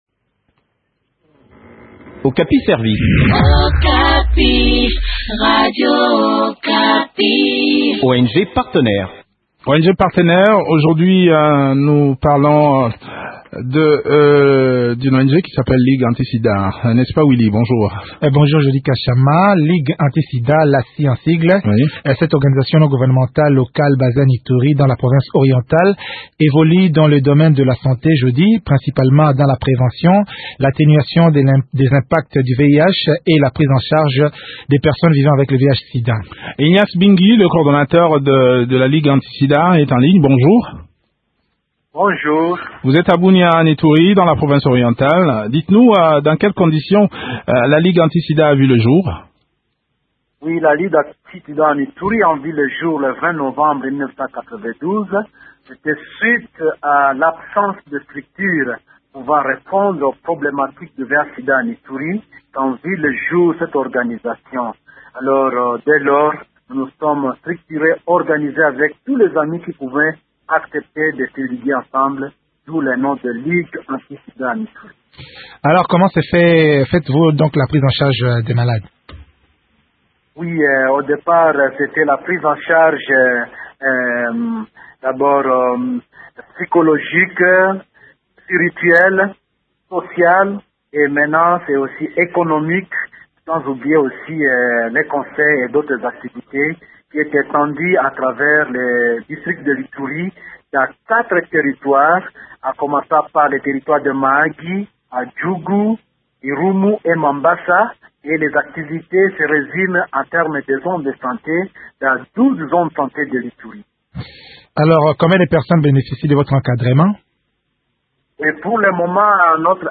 fait le point de leurs activités en Ituri au micro